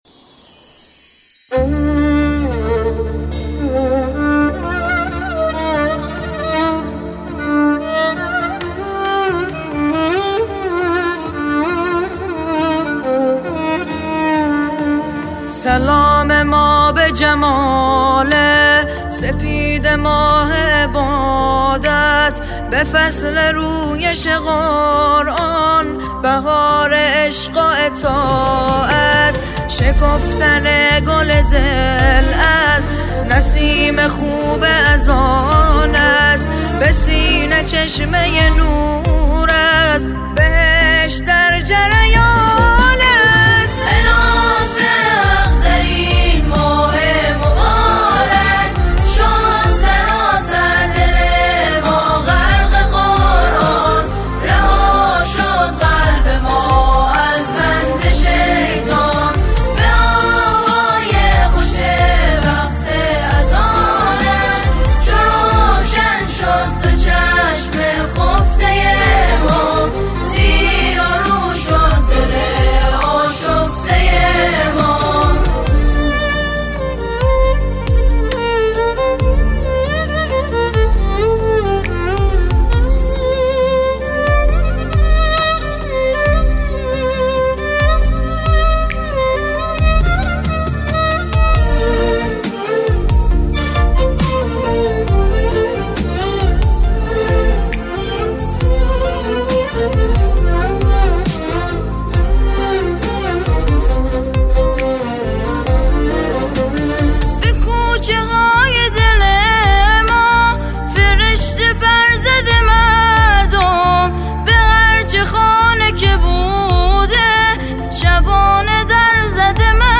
نماهنگ سرود زیبای